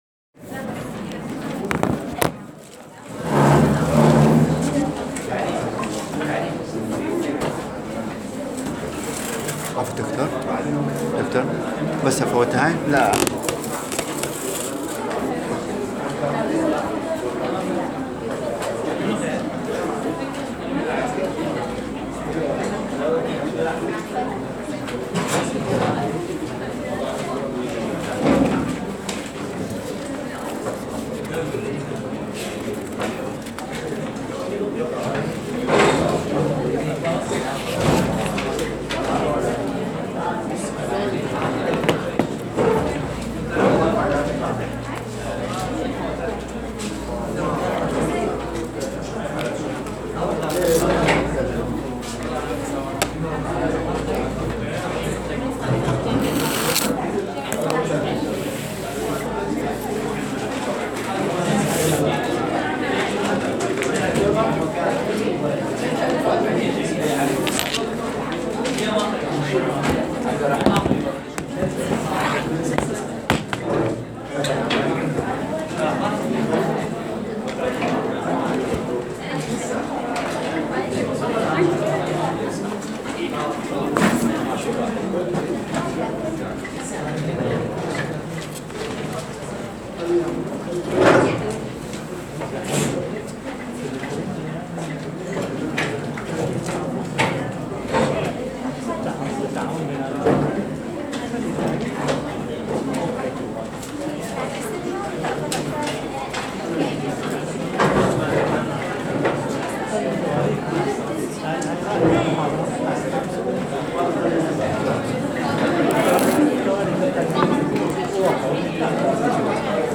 الفسلجة > محاضرة رقم 4 بتاريخ 2015-11-06